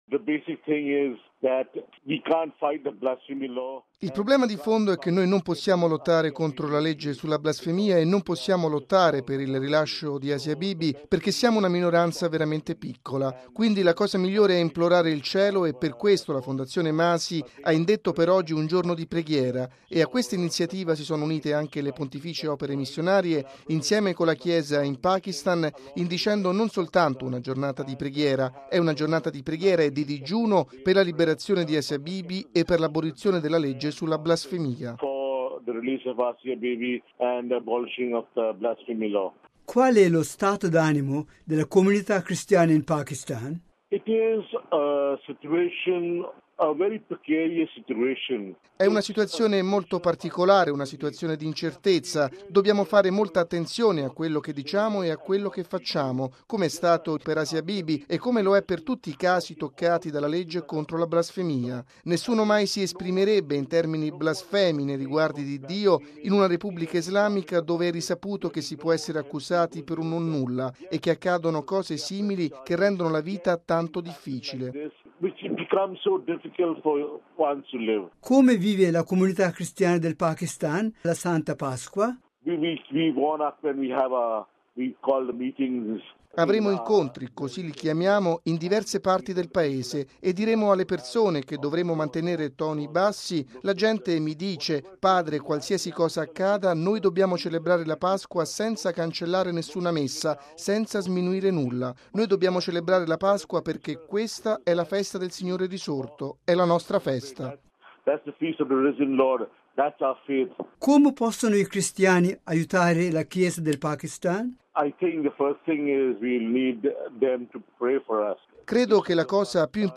ascoltiamo al microfono